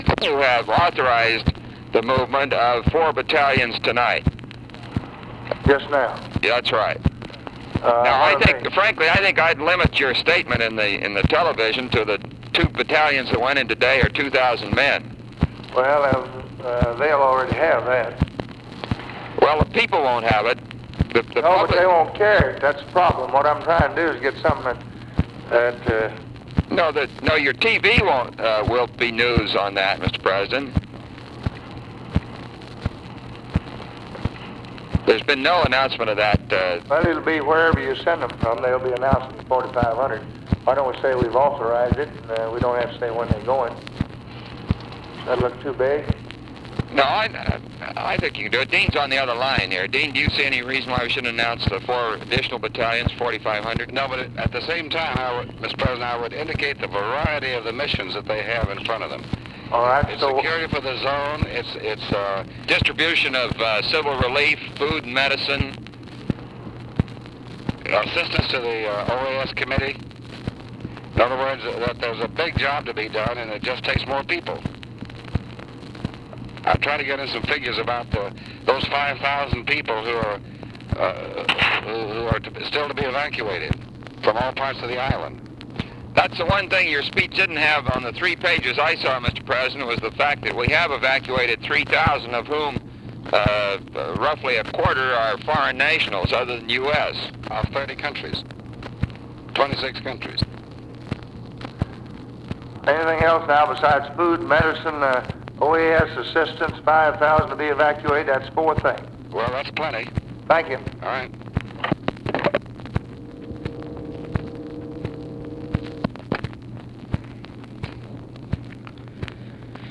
Conversation with ROBERT MCNAMARA and DEAN RUSK, May 3, 1965
Secret White House Tapes